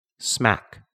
/smæk/